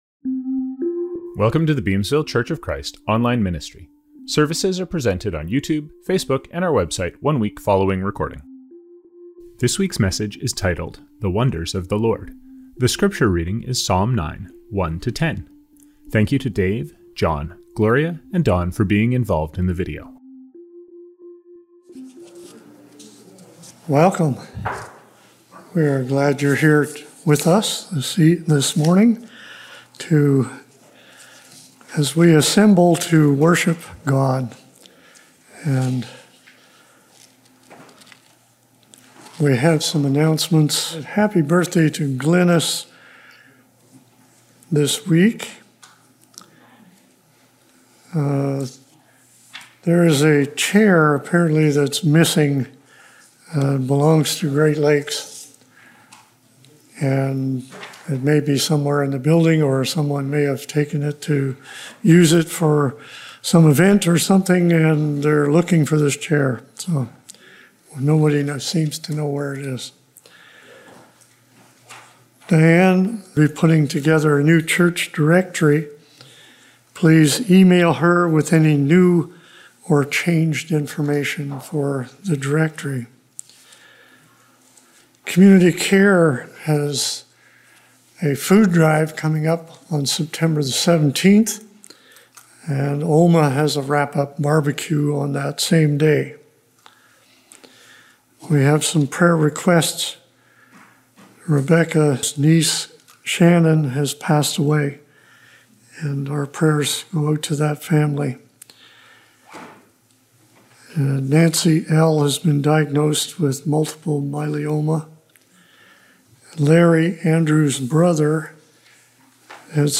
Songs from this service include
Reading: Psalm 9:1-10 (NIV).